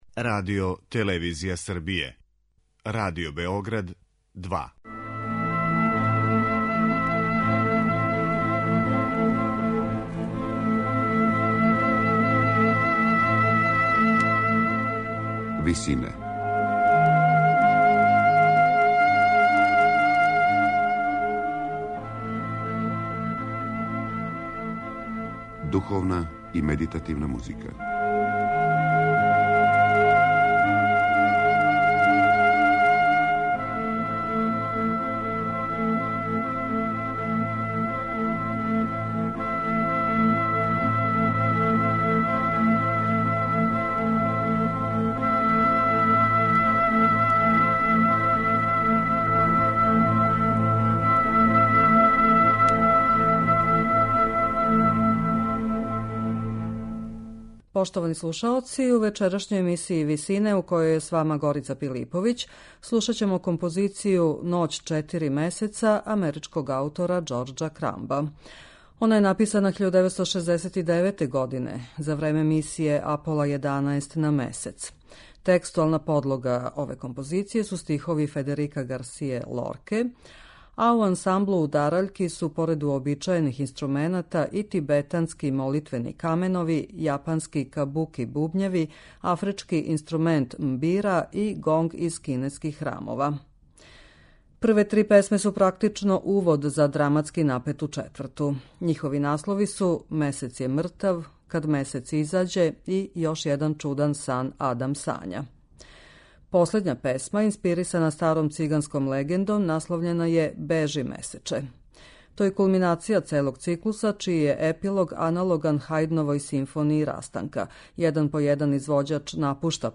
На крају програма, у ВИСИНАМА представљамо медитативне и духовне композиције аутора свих конфесија и епоха.
циклус песама